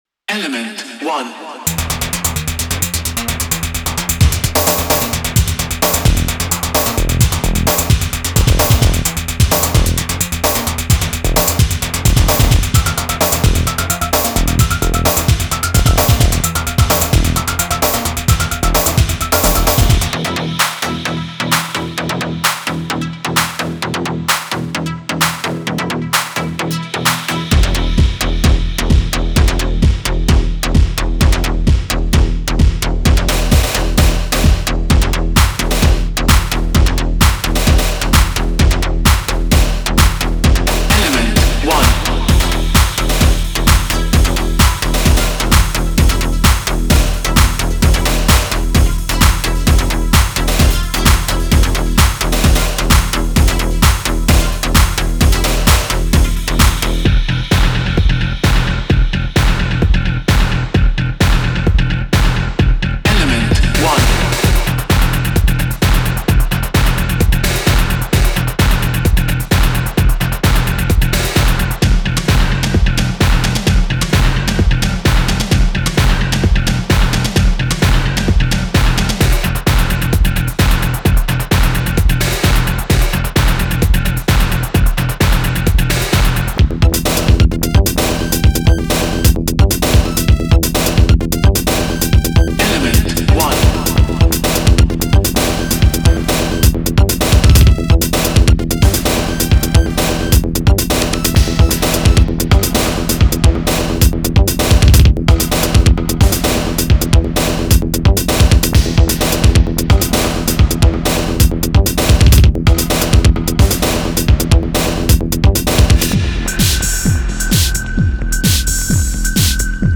Genre:Industrial Techno